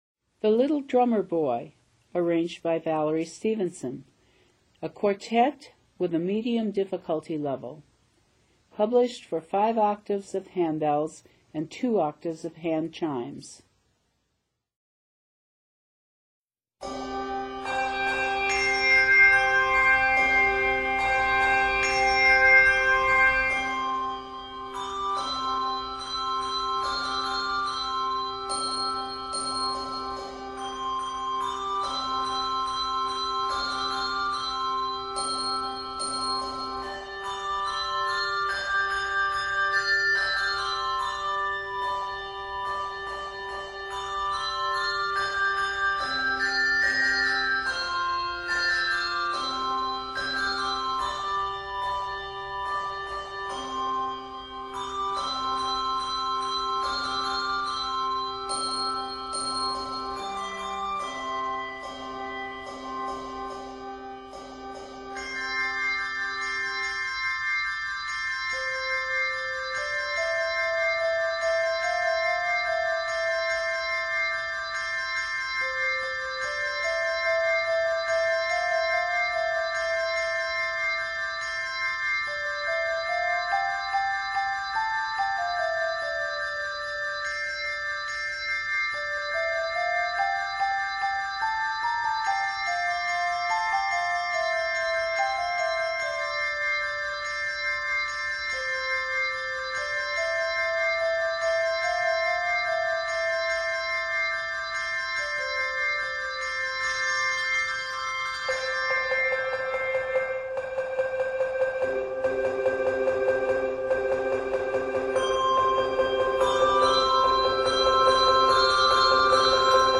is arranged in C Major and is 90 measures.